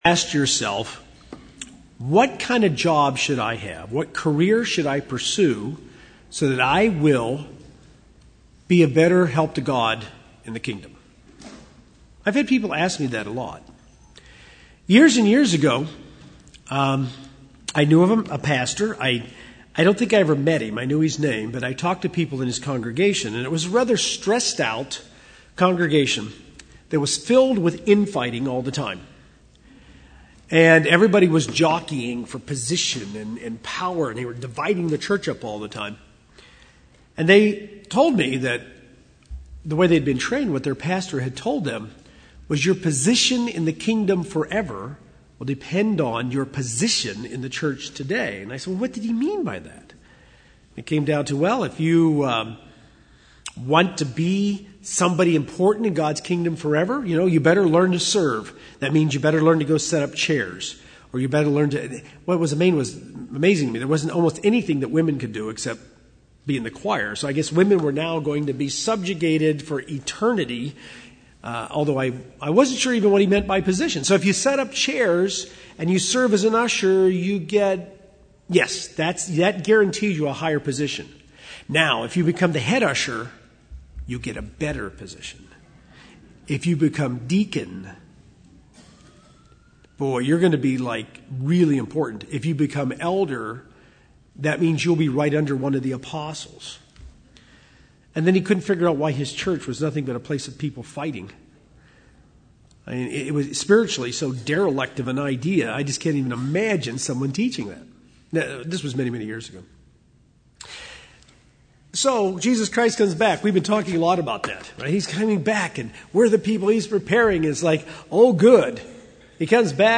Bible Study - Three Training Grounds Outside Your Employment
This sermon was given at the New Braunfels, Texas 2013 Feast site.